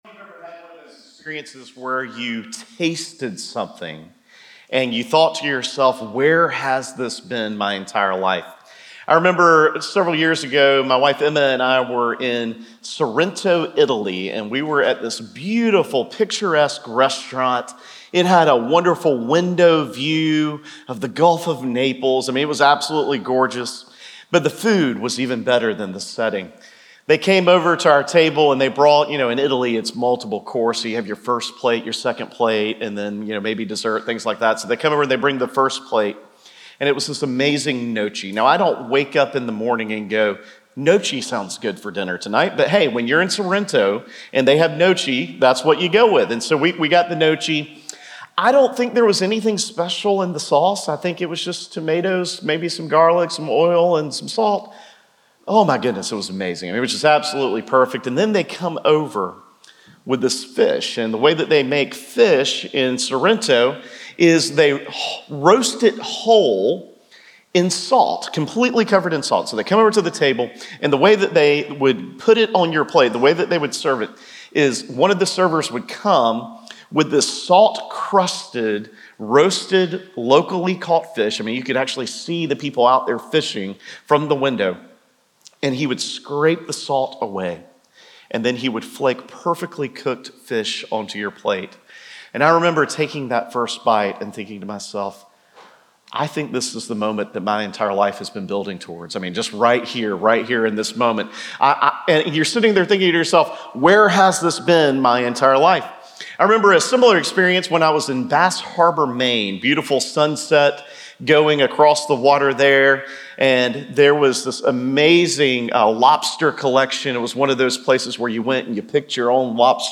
Sermon Audio – First Methodist Church of Valdosta